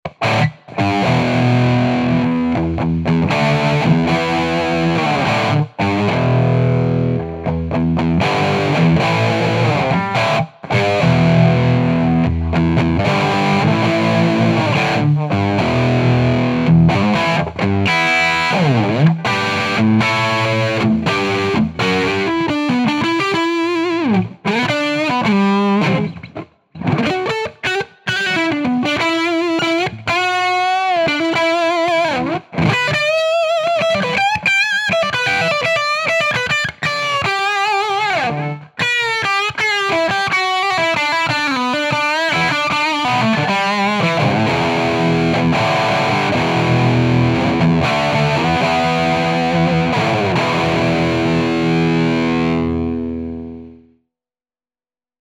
JCM 800 sa mi nelubi ani v reale, ale ta simulacia je celkom podarena:
Je to hrane na HB Gibson 498T / 490R.